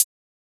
{HH} Razer.wav